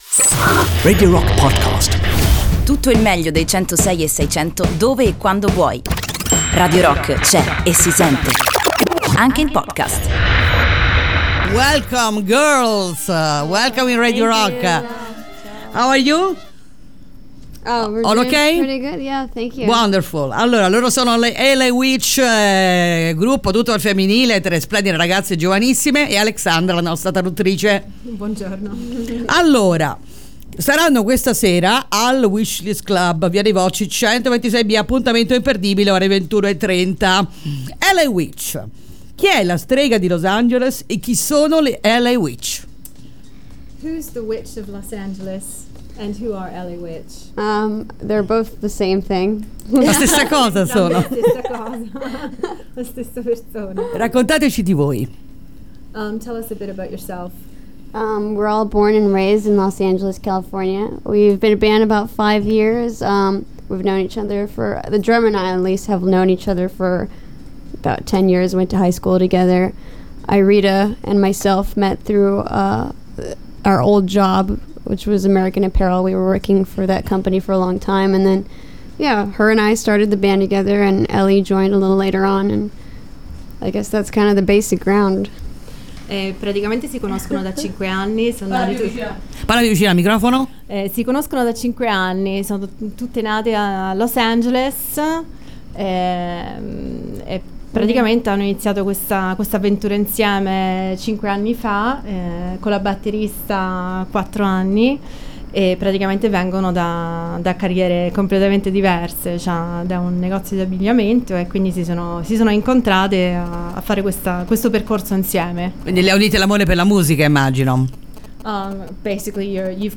Intervista: L.A. WITCH (06-06-18)